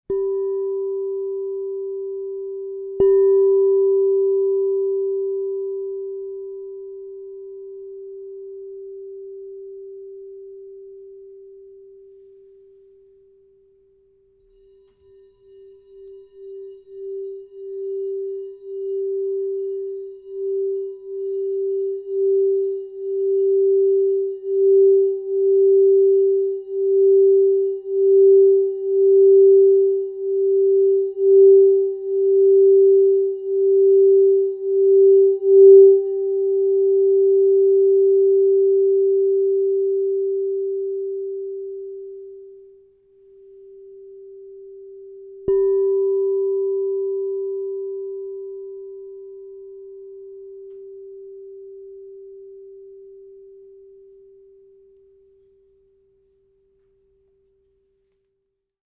St. Germain Sky 7" G -5 Crystal Tones Singing Bowl
This 7-inch True Tone instrument in the note of G -5 is harmonically aligned within 10 cents of standard tuning, meaning it can easily harmonize with traditionally tuned musical instruments. True Tone bowls are especially valued for their ability to provide vibrational purity and consistent resonance, supporting meditation, chakra healing, and sound baths.
432Hz (-), 440Hz (TrueTone)